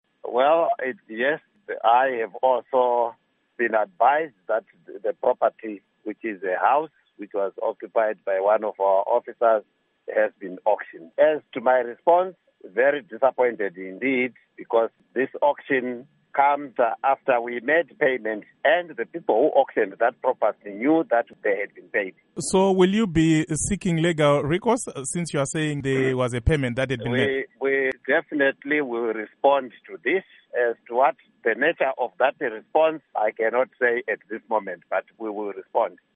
Interview With Ambassador Isaac Moyo on Auctioning of Zimbabwe Property